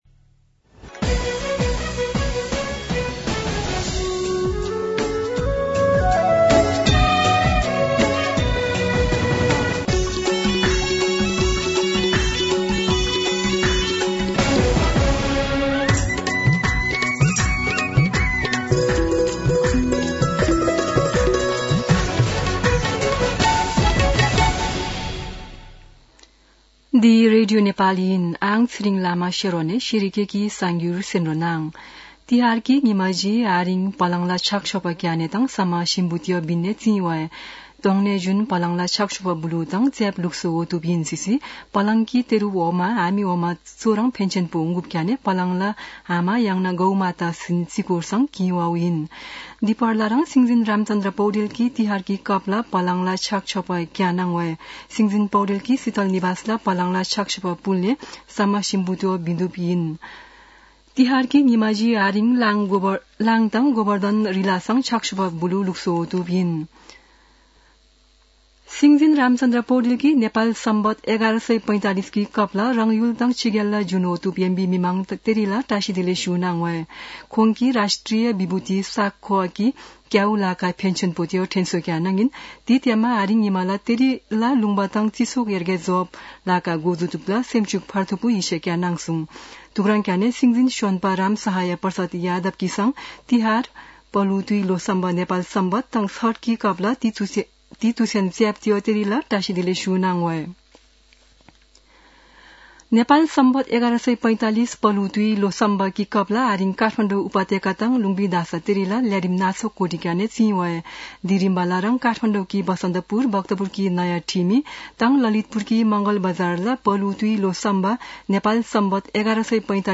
शेर्पा भाषाको समाचार : १८ कार्तिक , २०८१